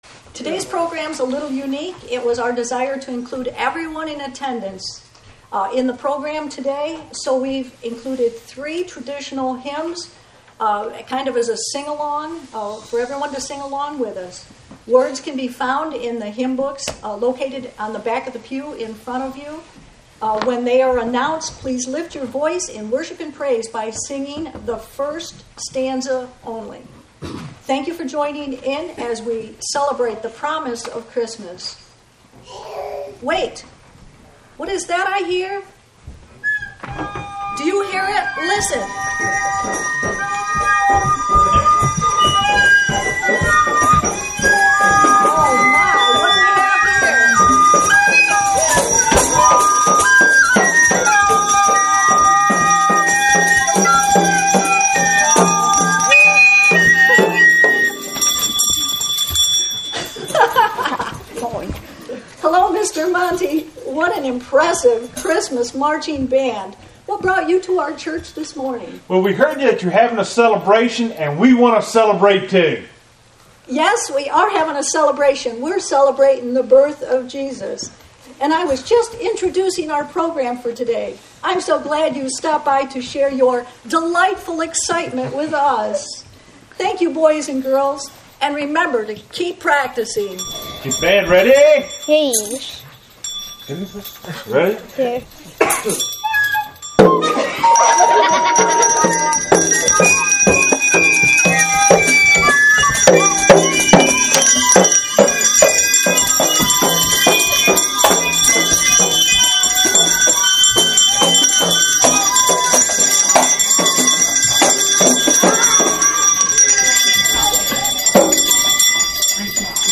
Christmas Program – “The Promise of Christmas”